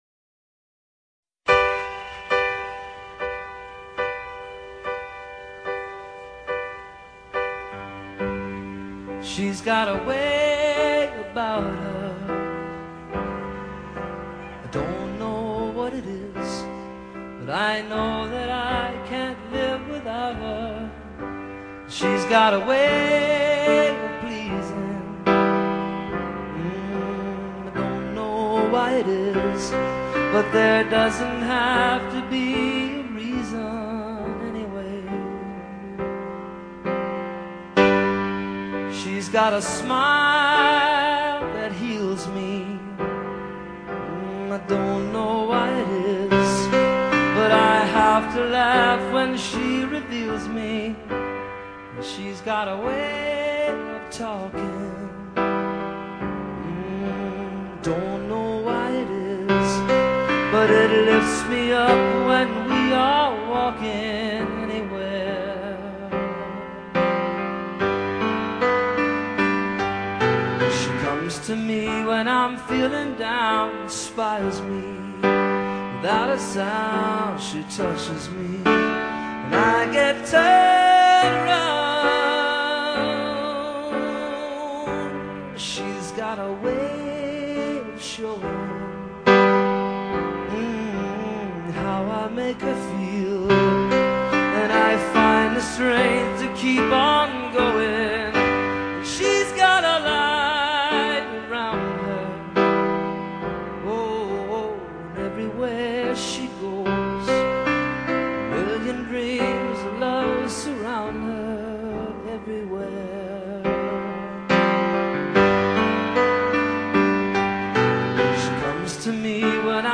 Entrevista a Jon Secada
Entrevistado: "Jon Secada"